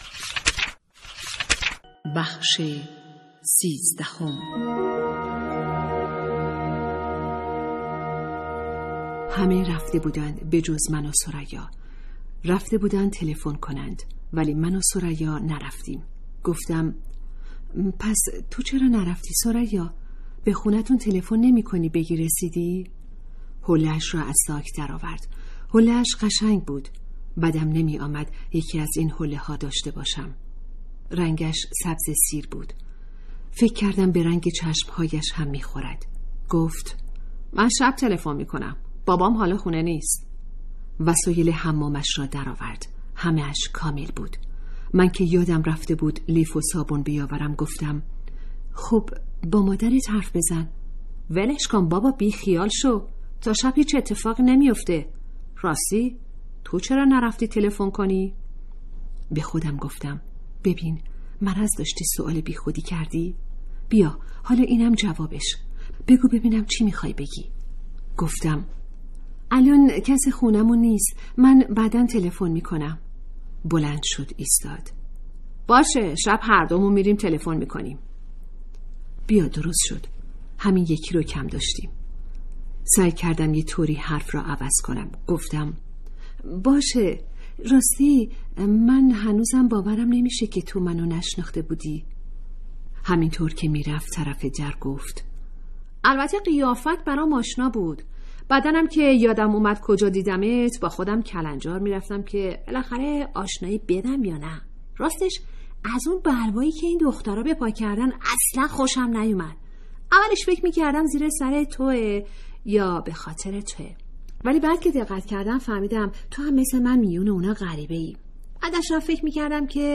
کتاب صوتی | دختران آفتاب (13)